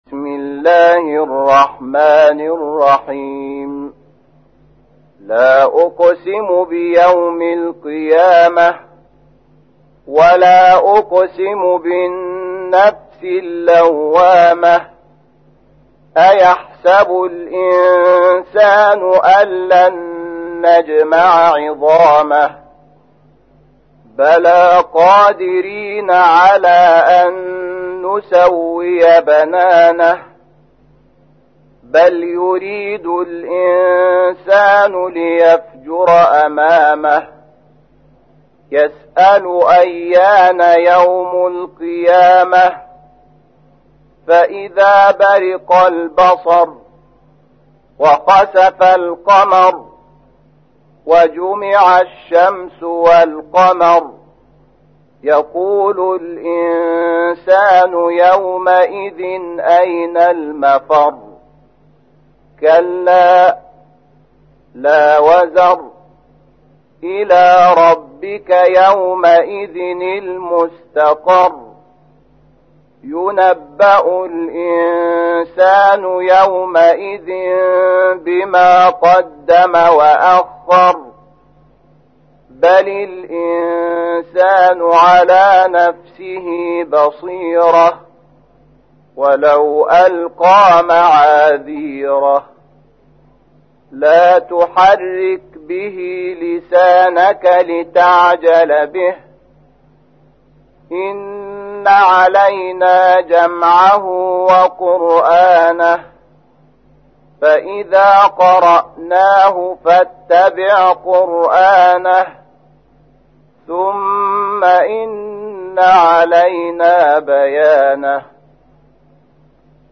تحميل : 75. سورة القيامة / القارئ شحات محمد انور / القرآن الكريم / موقع يا حسين